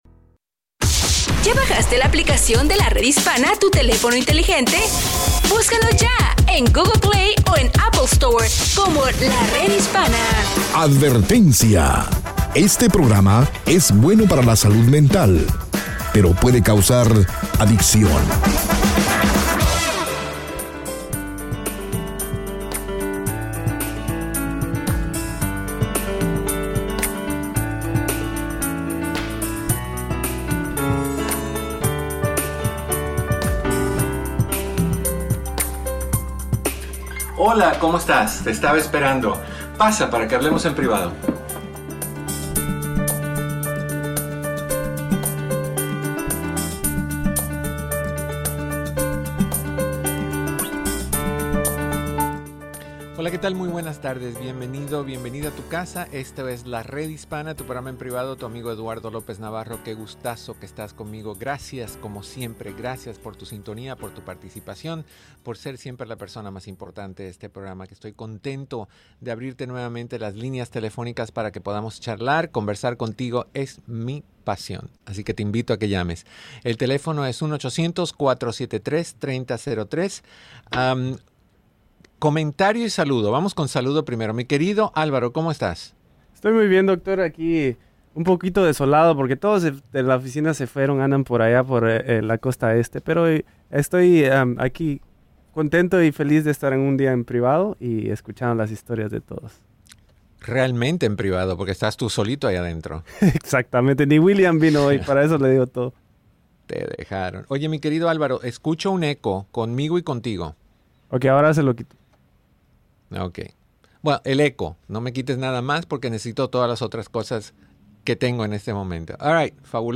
Escucha el programa de radio EN PRIVADO, de Lunes a Viernes a las 2 P.M. hora del Pacífico, 4 P.M. hora Central y 5 P.M. hora del Este por La Red Hispana y todas sus afiliadas.